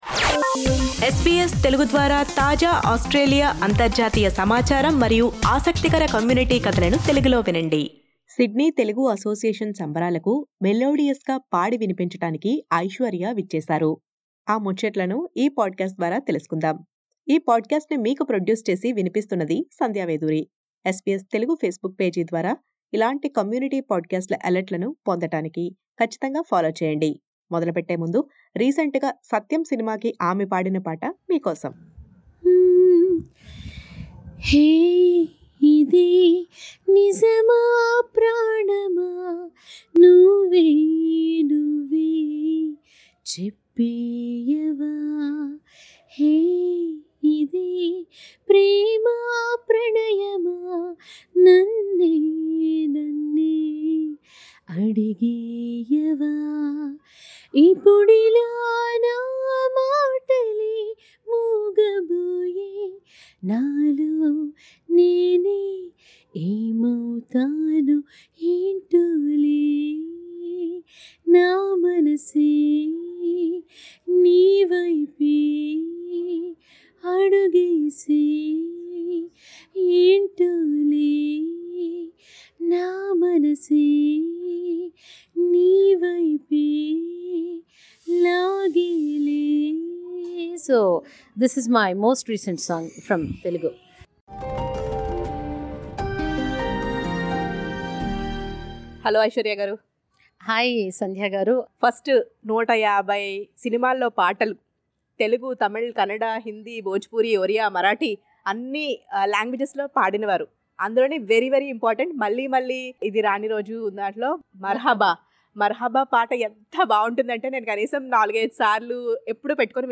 special interview